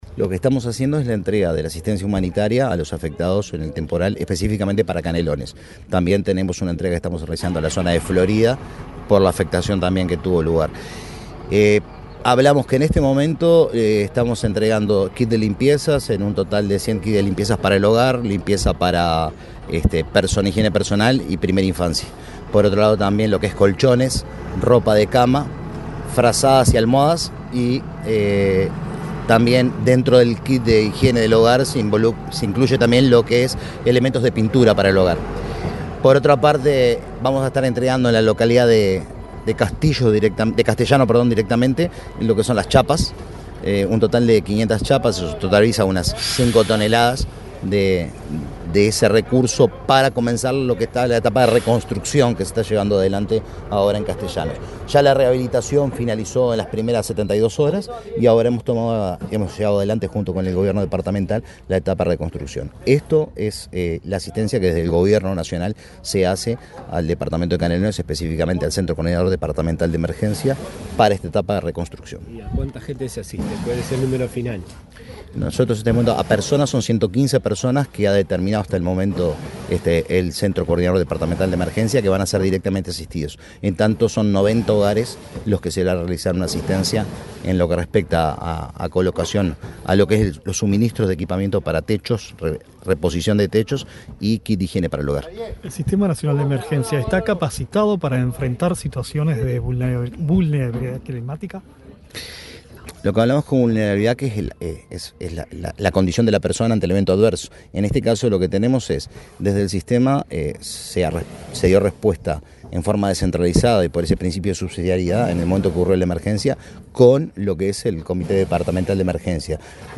Declaraciones del director del Sinae, Leandro Palomeque
El director del Sistema Nacional de Emergencias (Sinae), Leandro Palomeque, dialogó con la prensa, durante su recorrida por Canelones, donde entregó